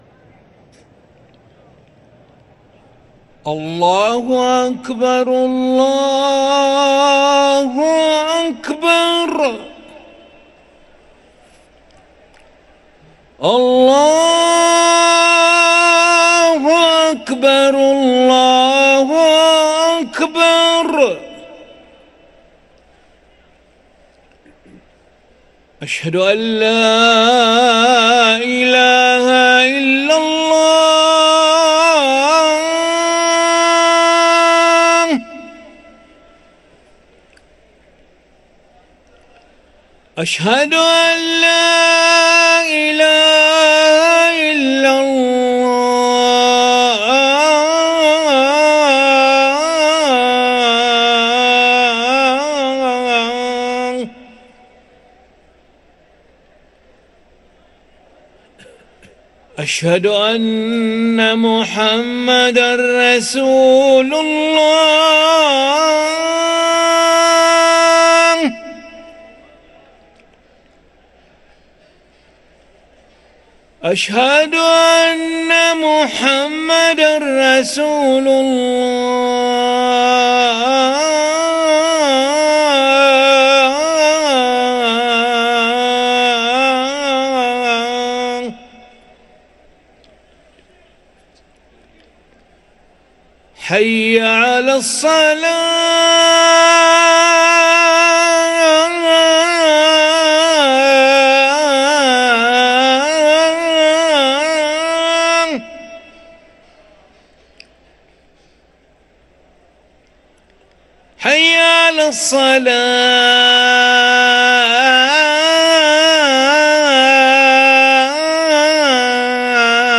أذان العشاء للمؤذن علي ملا الخميس 22 رمضان 1444هـ > ١٤٤٤ 🕋 > ركن الأذان 🕋 > المزيد - تلاوات الحرمين